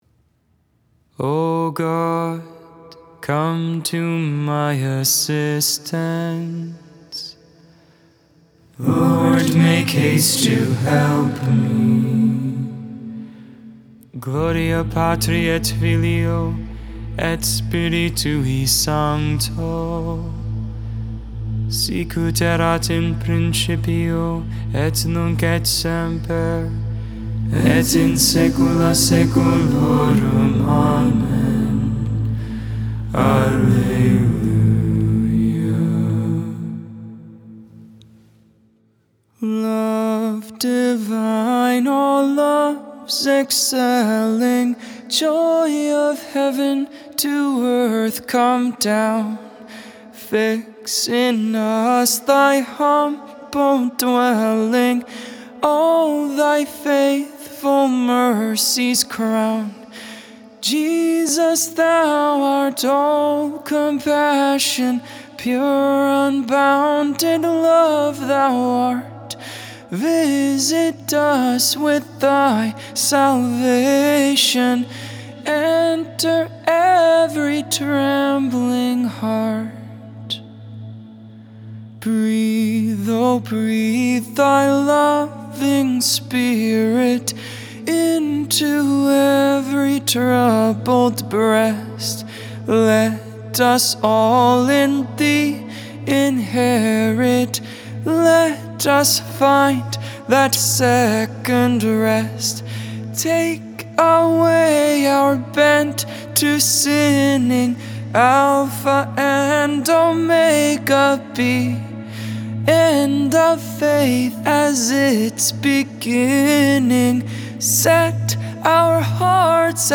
2.14.22 Vespers, Monday Evening Prayer